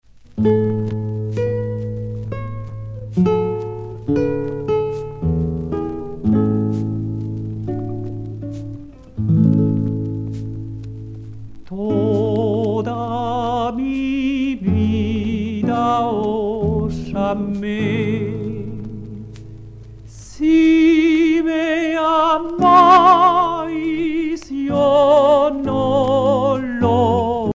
enfantine : prière, cantique
Genre strophique
Pièce musicale éditée